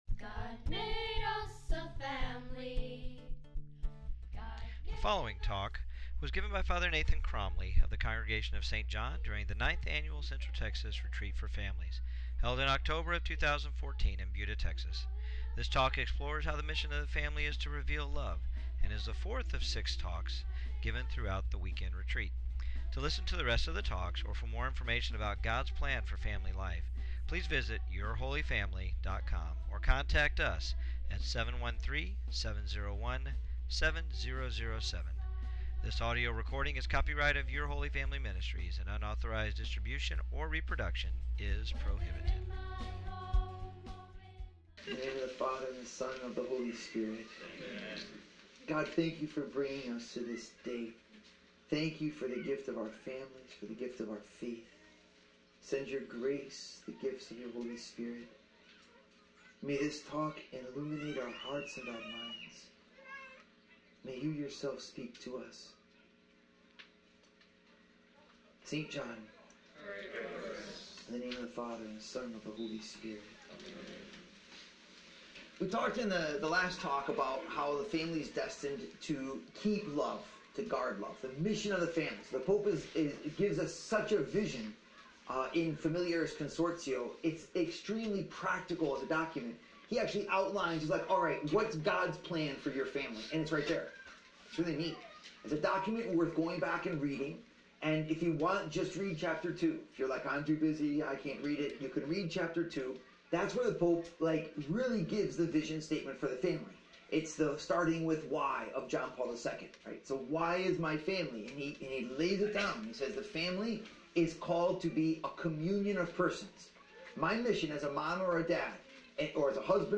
The Mission of the Family is to Guard, Reveal and Communicate Love and this fourth talk of the 2014 Central Texas Retreat for Families provides thoughtful reflection on the mission of the family to Reveal Love to the world.